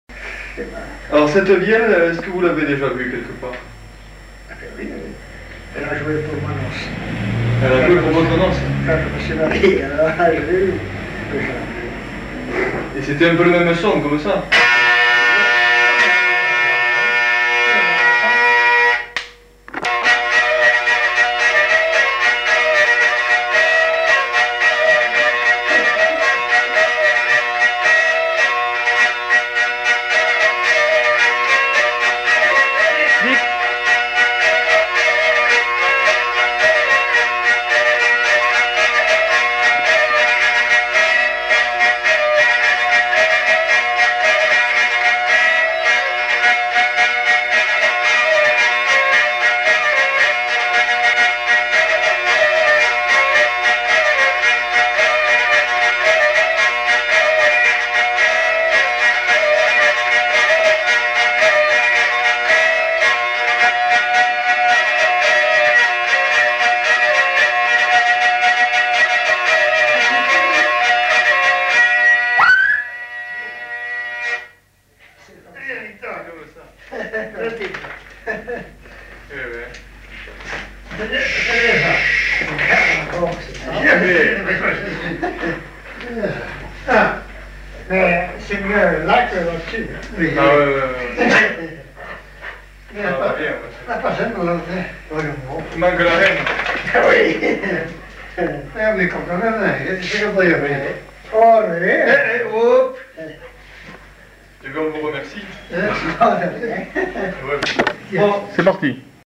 Aire culturelle : Petites-Landes
Genre : morceau instrumental
Instrument de musique : vielle à roue
Danse : rondeau